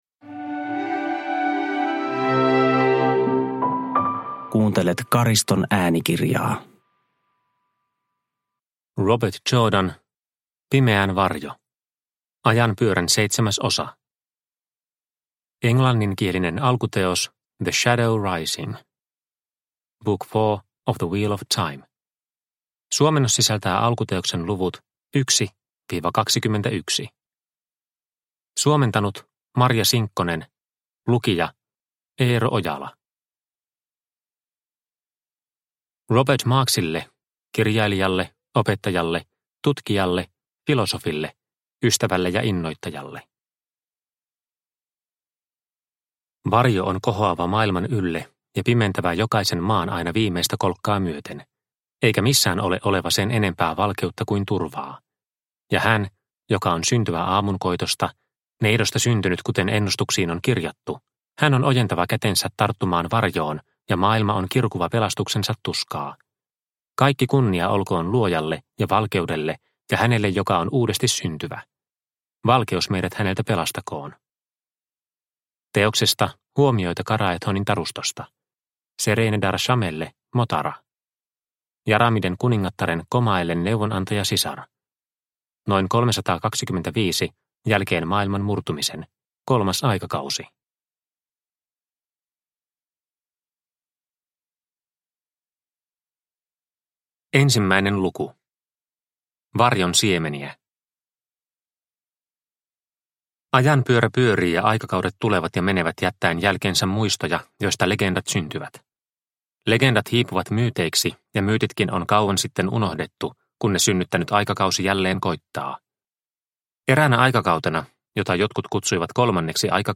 Pimeän varjo – Ljudbok – Laddas ner